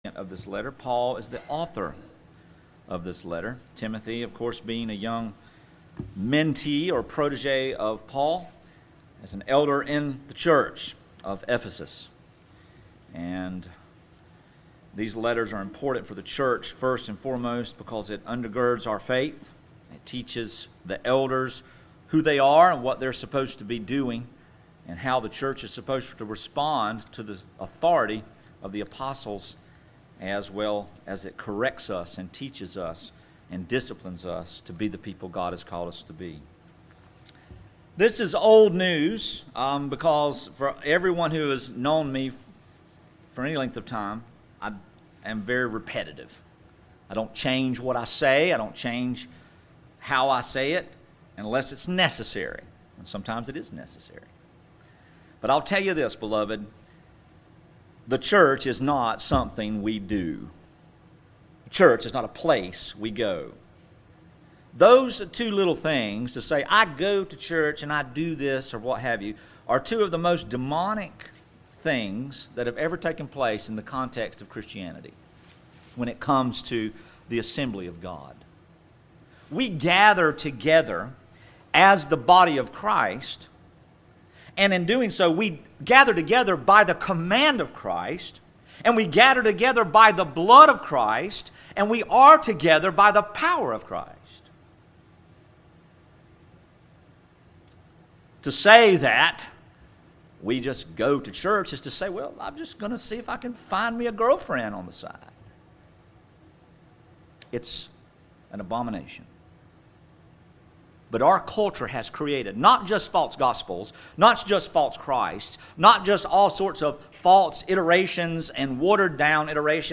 W5 Peace, Peace, Peace Pt1 | SermonAudio Broadcaster is Live View the Live Stream Share this sermon Disabled by adblocker Copy URL Copied!